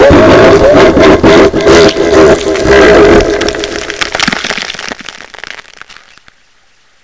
pain75_1.wav